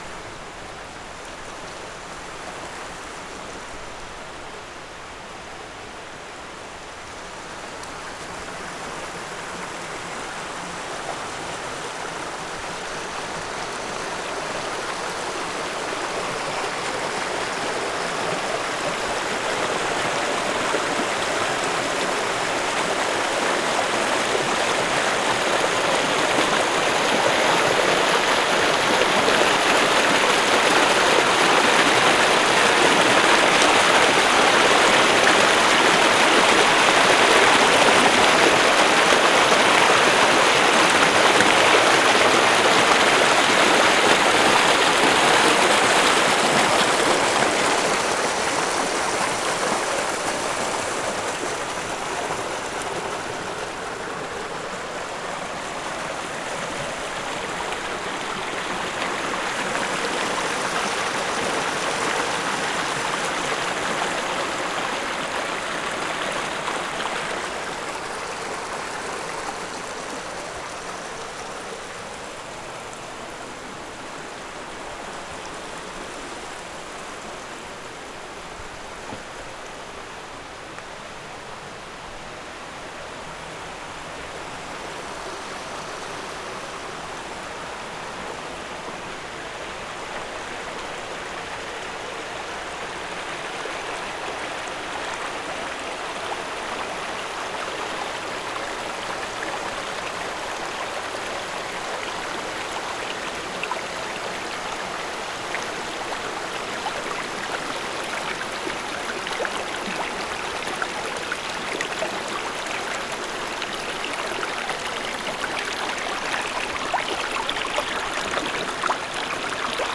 HORSESHOE BAY, SURROUNDING AREA NOV. 3, 1991
soundwalk along stream 9:14
13. streamwalk with several close-up recorded events along the way, nice bubbling and gurgling at 1:58, nice close-up gurgling at 4:24